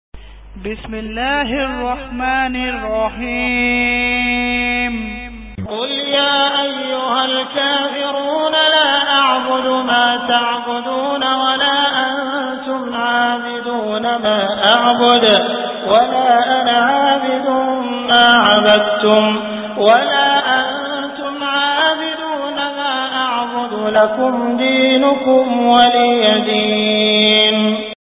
Surah Al Kafirun Beautiful Recitation MP3 Download By Abdul Rahman Al Sudais in best audio quality.